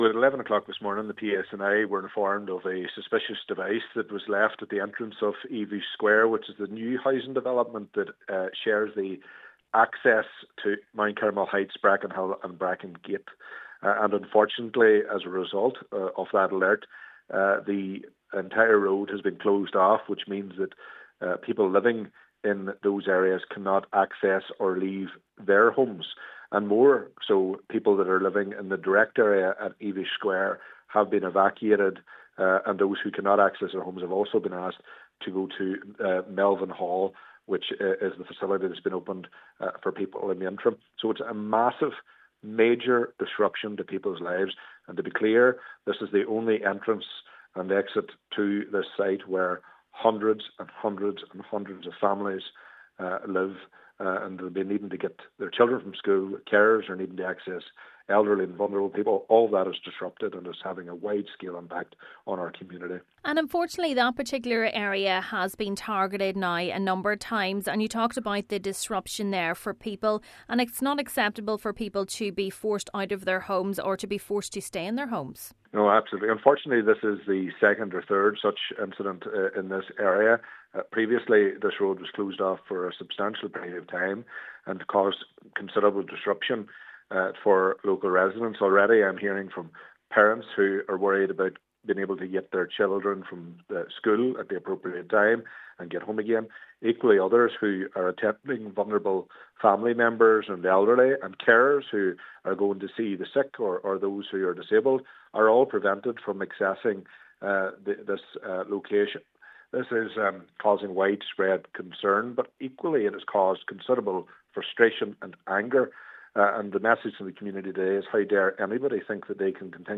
West Tyrone MLA Daniel McCrossan has condemned the actions of those responsible: